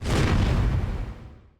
shake.opus